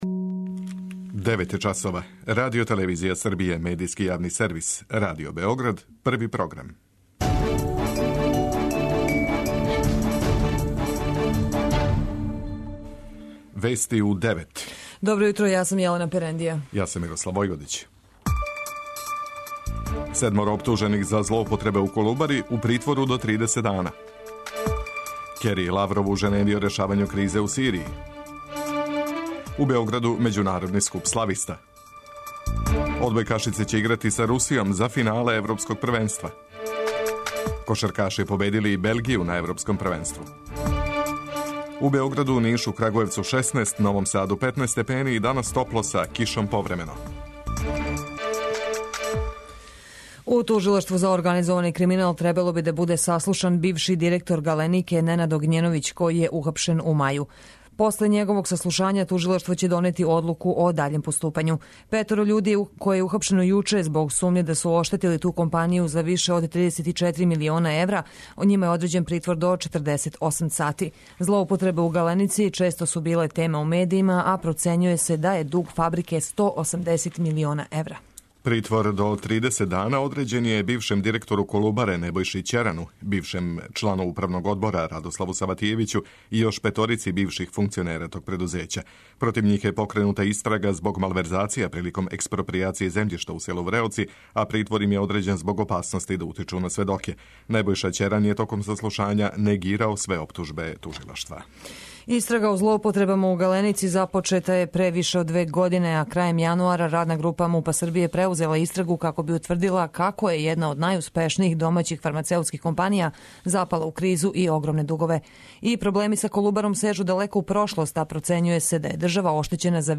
Вести уређују и воде: